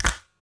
07_Perc_03_SP.wav